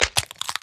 Minecraft Version Minecraft Version latest Latest Release | Latest Snapshot latest / assets / minecraft / sounds / mob / turtle / egg / egg_crack5.ogg Compare With Compare With Latest Release | Latest Snapshot
egg_crack5.ogg